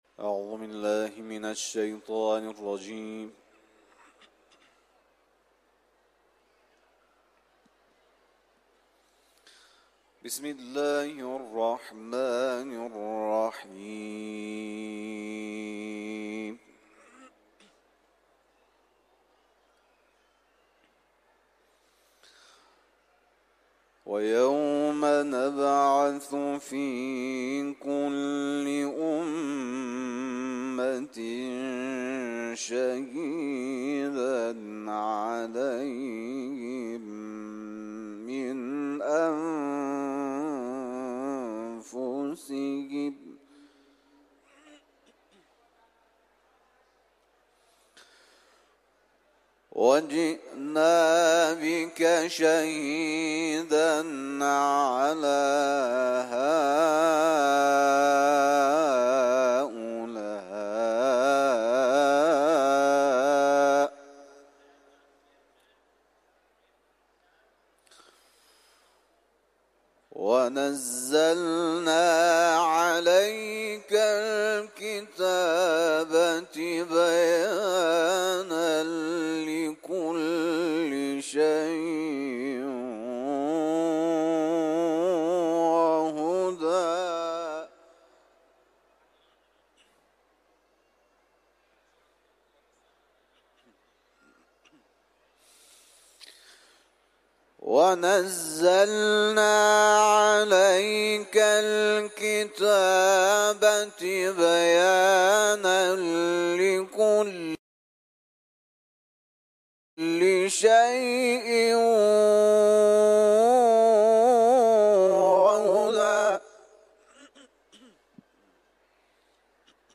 سوره نحل ، تلاوت قرآن